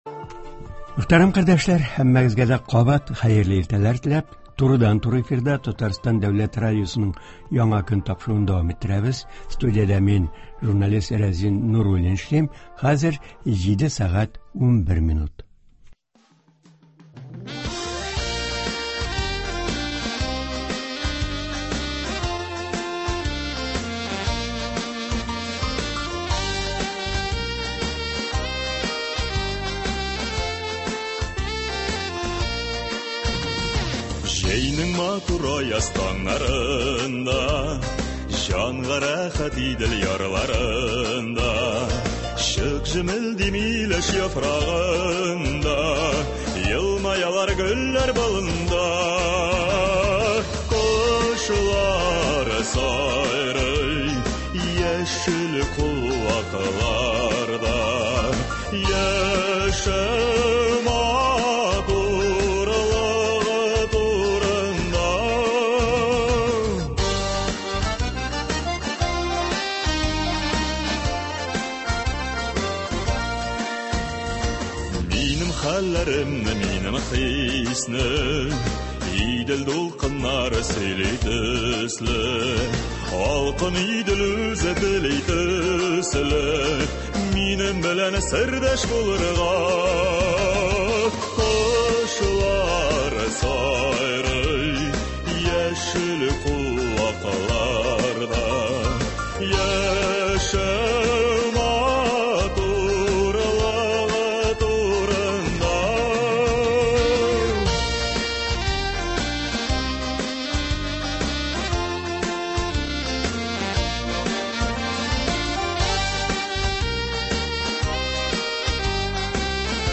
Туры эфир (05.07.23)